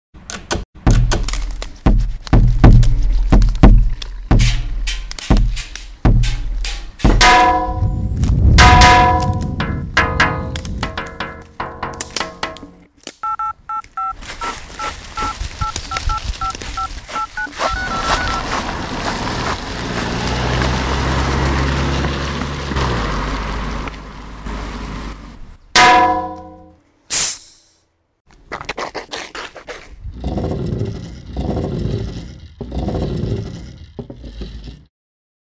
Aus vielen einzelnen Geräuschen haben die Jugendlichen der Our Voice-Redaktion rhythmische Collagen geschnitten.
Auf der Jagd nach Geräuschen pirschten sich die Jugendlichen mit ihren Aufnahmegeräten in den nahen Wald und zeigten sich überaus kreativ, was die selbständige Produktion von Geräuschen angeht.
Die einen schlagen verschiedene Stöcke gegen Bäume und bekommen jeweils deutlich unterscheidbare Schlaggeräusche. Andere erzeugen eine Vielzahl an Schritt-, Schlurf-, oder Stampfgeräusche, je nach Untergrund und Mikrofonposition variieren die Geräusche erheblich.
Ein Auto fährt vorbei, der Bach plätschert - aufnehmen und wahrnehmen, was uns im Alltag kaum auffällt. Und dann selbst aktiv werden: ein geworfener Stein prallt auf ein Straßenschild liefert ein überraschendes Dong, der über den Steinboden gezogene Sessel klingt wie ein brüllender Löwe.